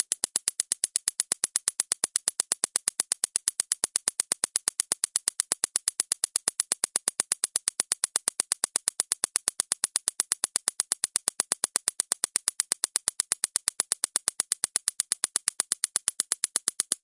警察电击棒
描述：一个警察的250Milion伏特电击自卫电击枪。